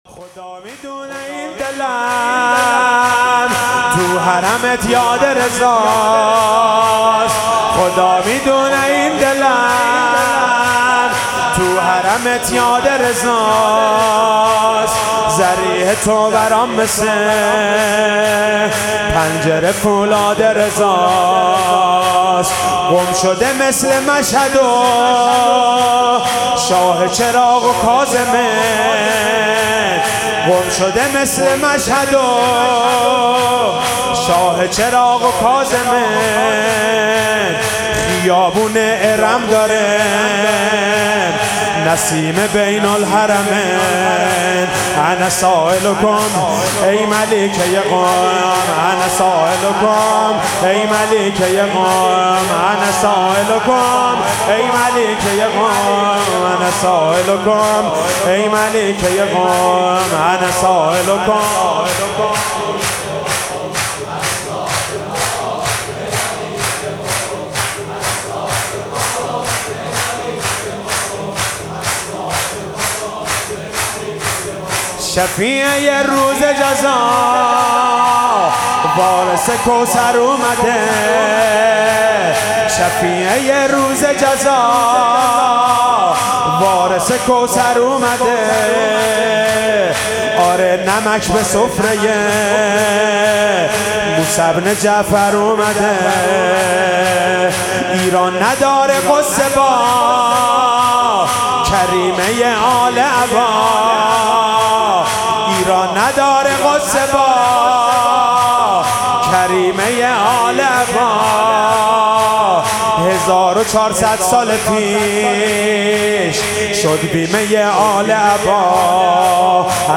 ولادت حضرت معصومه (س) 98 - سرود - خدا میدونه این دلم